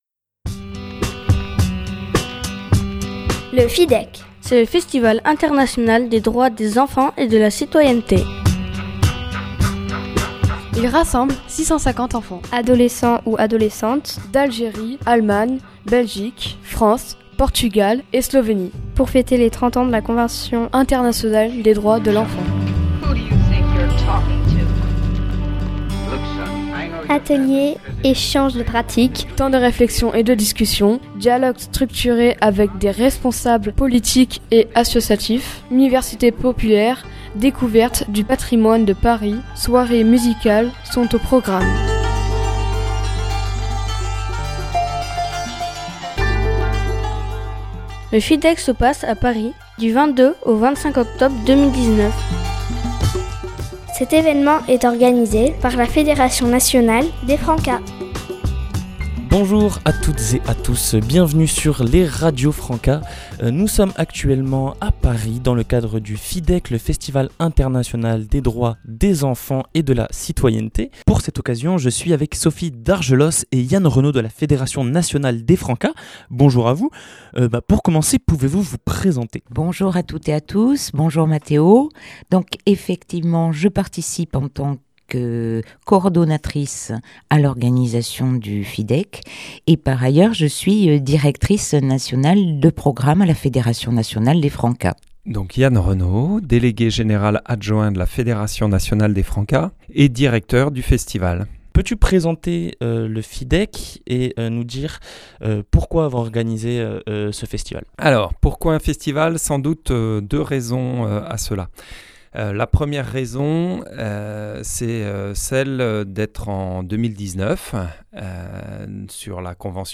Reportage sur le 1er FIDEC (Festival International des Droits des enfants et de la citoyenneté)
Ce reportage a été réalisé par L'équipe des Radios Francas de la Fédération nationale des Francas sur Paris durant 4 Jours .